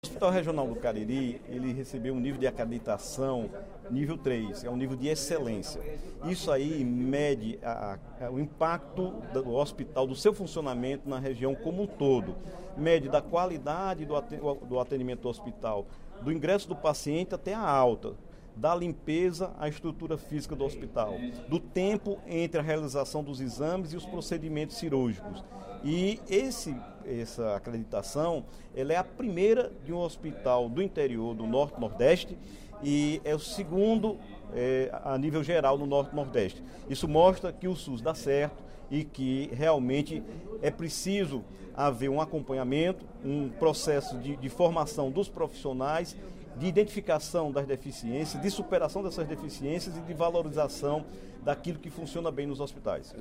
O deputado Dr. Santana (PT) destacou, nesta quarta-feira (22/02), durante o primeiro expediente da sessão plenária da Assembleia Legislativa, que o Hospital Regional do Cariri foi reconhecido pela excelência nos serviços oferecidos à população com o título Acreditado com Excelência – Nível III.